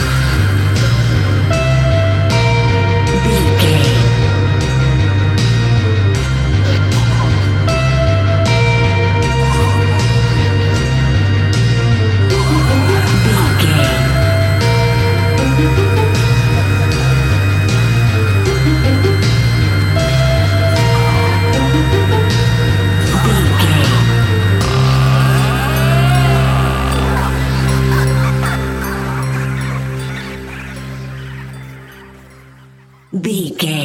Aeolian/Minor
ominous
haunting
eerie
strings
synthesiser
percussion
electric guitar
drums
electric organ
harp
tense
spooky
horror music